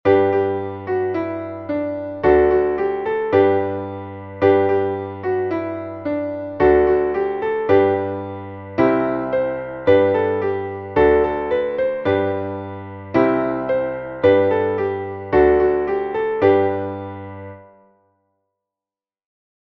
Traditionelles Lied aus Afrika (Simbabwe | Shona-Volk)